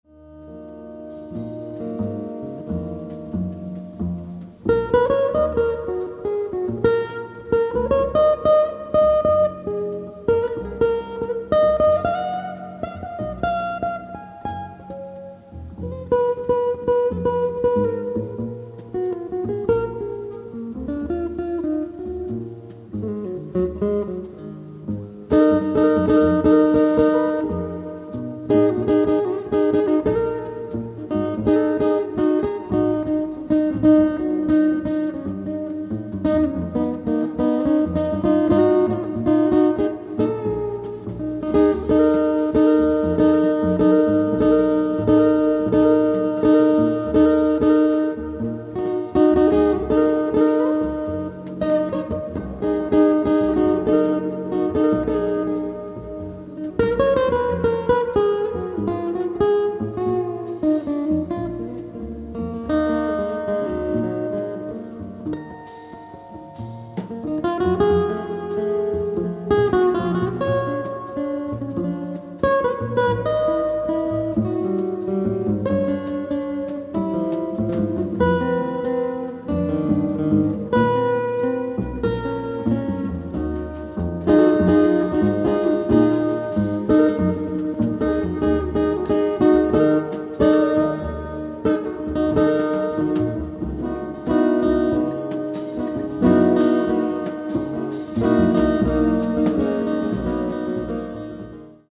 ライブ・アット・シアター・アンティーク、ビエンヌ、フランス 07/05/1999
※試聴用に実際より音質を落としています。